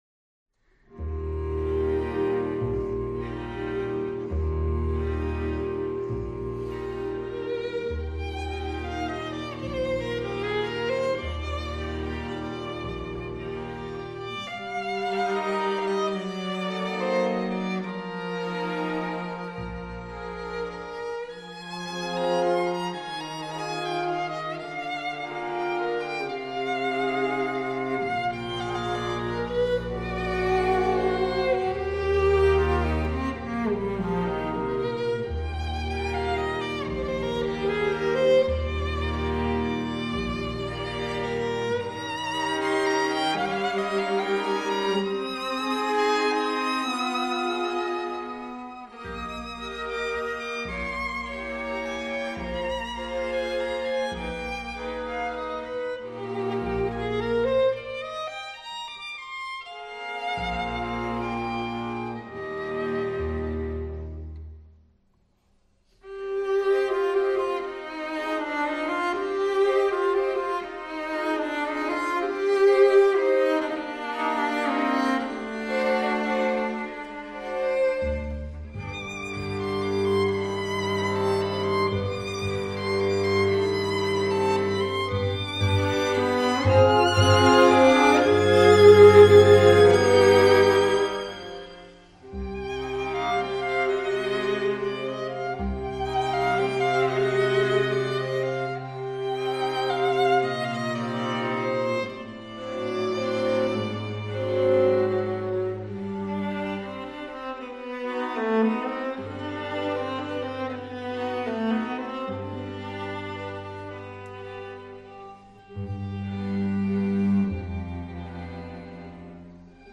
for 2 Violins, Viola, Cello and Bass
short, very romantic and lyrical tone poem
It is in three related sections.